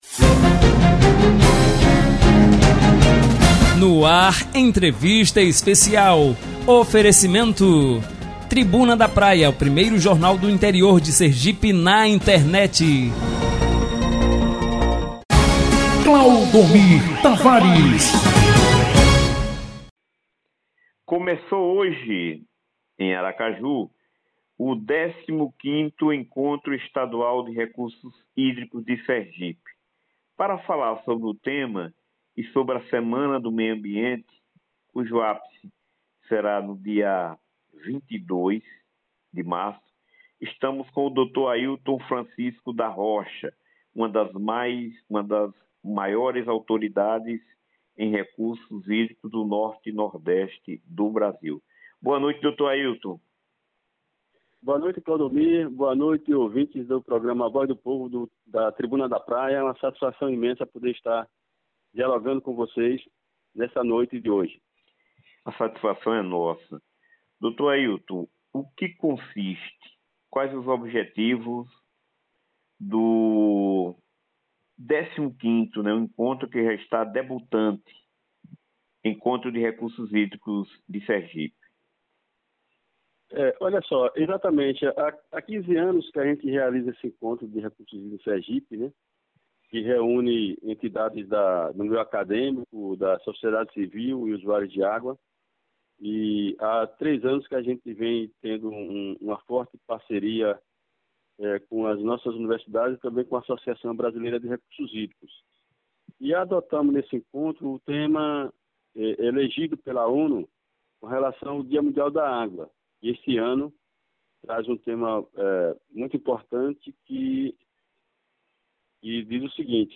Confira abaixo na íntegra o áudio da entrevista liberada na manhã de hoje pela emissora: